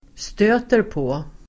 Uttal: [stö:terp'å:]